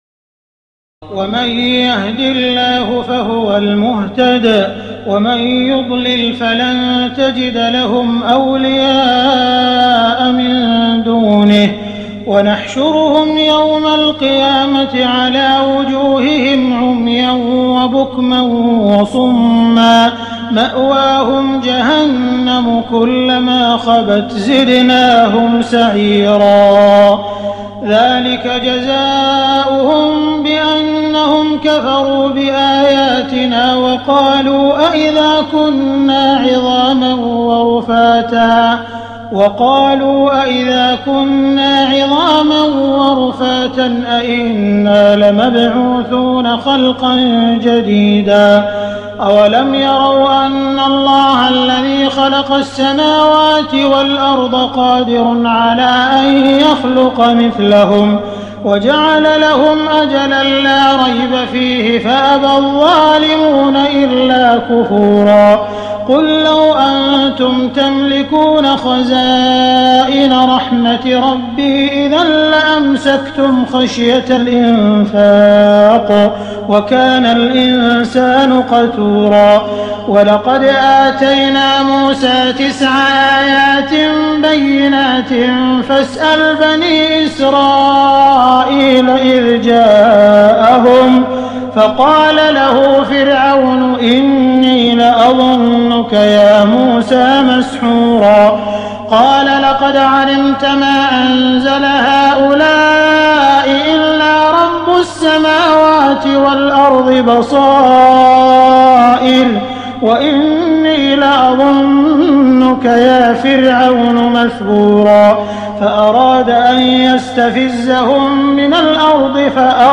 تراويح الليلة الرابعة عشر رمضان 1419هـ من سورتي الإسراء (97-111) و الكهف (1-82) Taraweeh 14 st night Ramadan 1419H from Surah Al-Israa and Al-Kahf > تراويح الحرم المكي عام 1419 🕋 > التراويح - تلاوات الحرمين